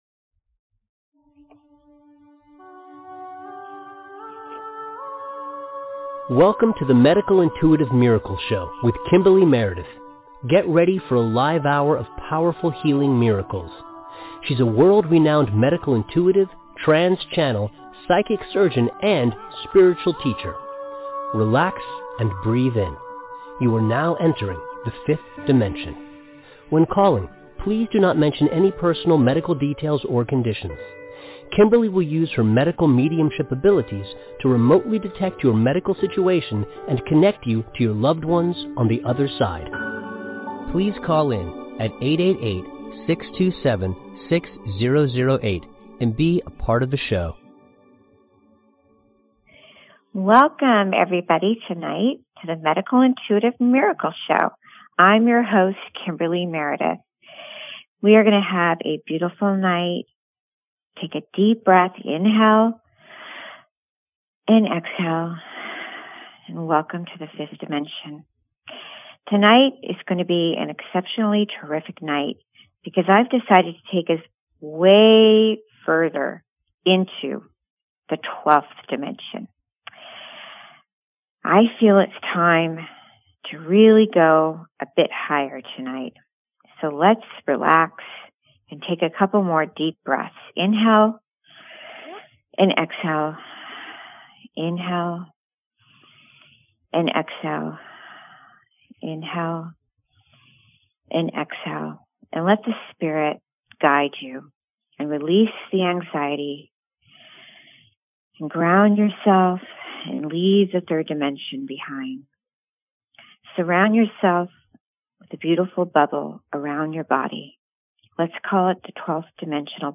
Open Lines